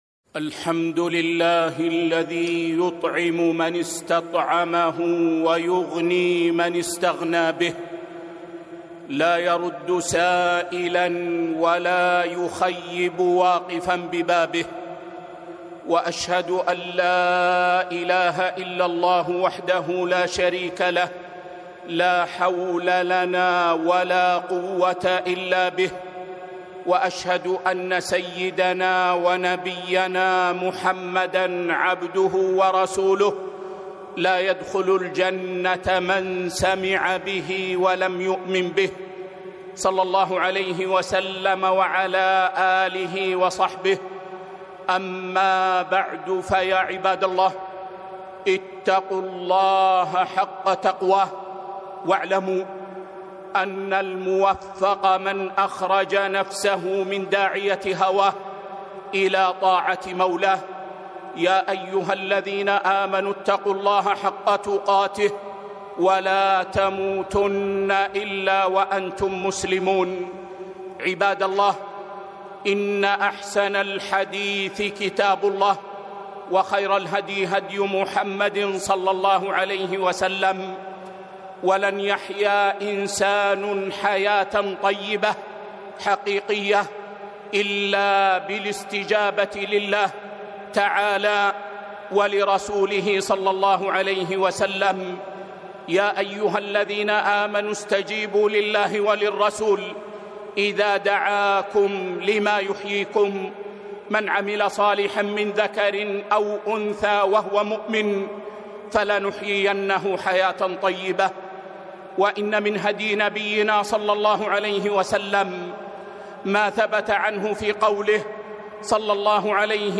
خطبة - من يستعفف يعفه الله